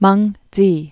(mŭngdzē)